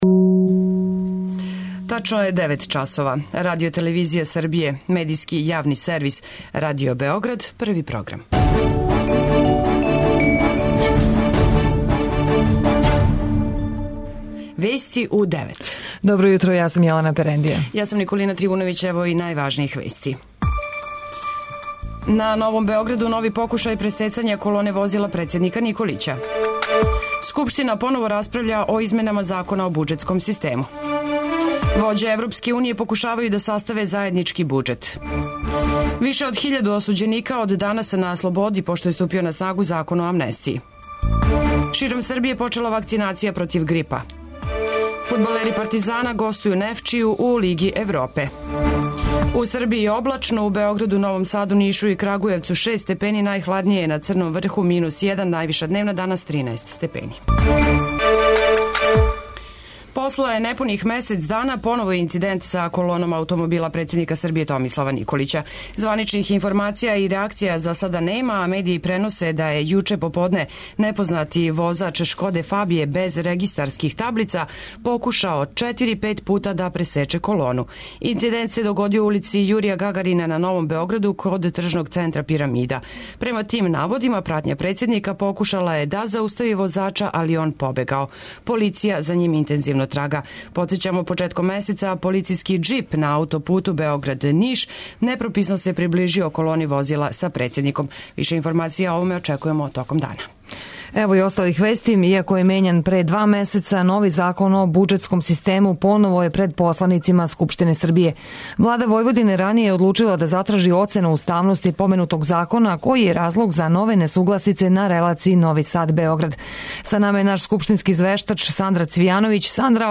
преузми : 10.09 MB Вести у 9 Autor: разни аутори Преглед најважнијиx информација из земље из света.